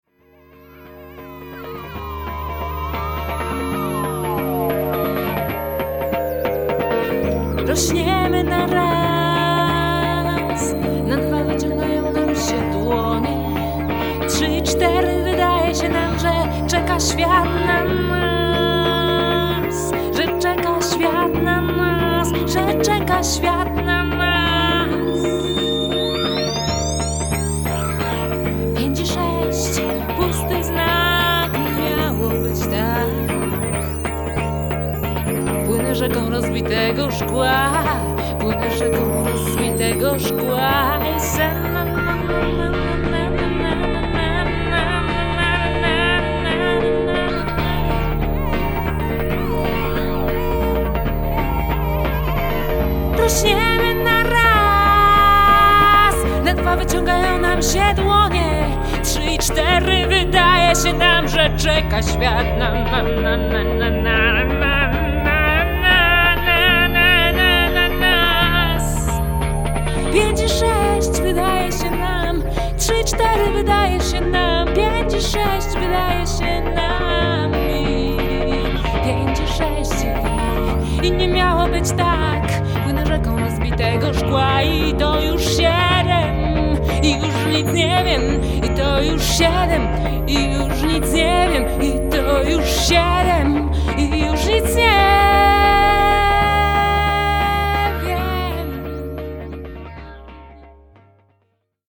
electric guitar
powerful throat singing